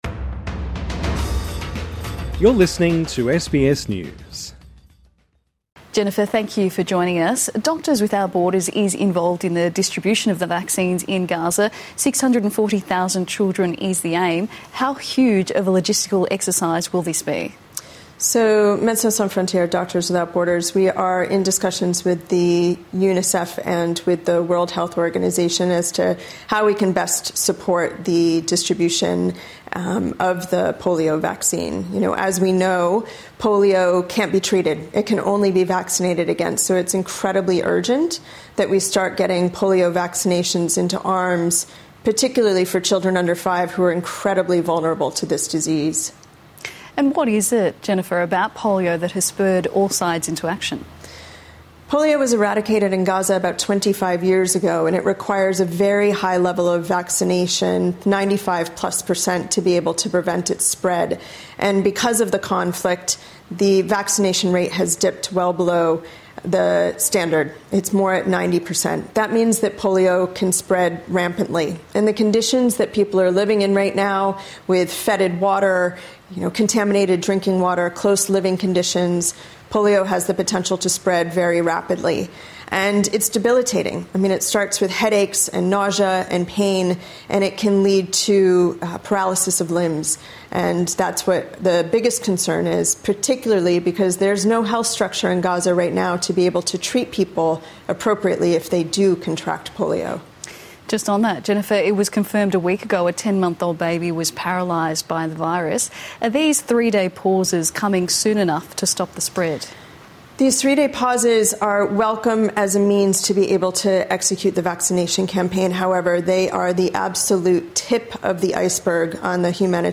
INTERVIEW: Polio crisis in Gaza pauses the war